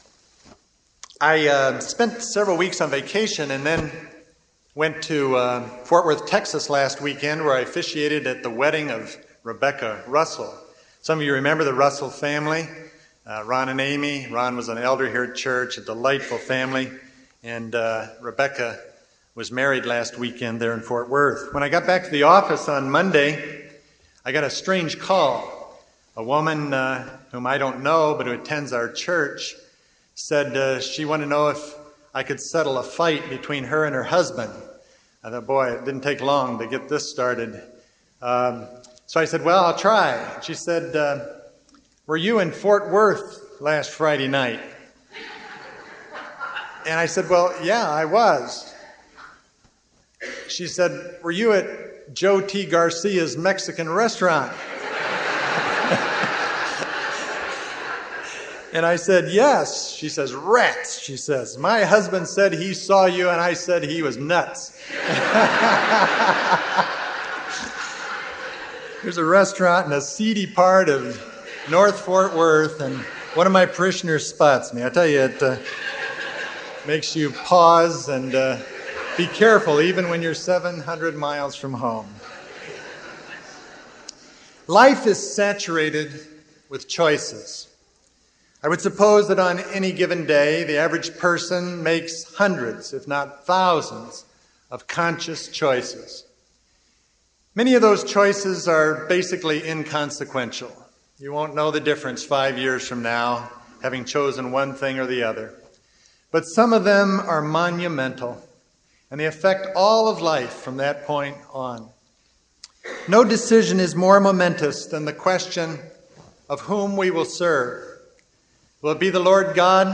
Undoubtedly most of you here this morning have made that salvation decision as to whom you will serve.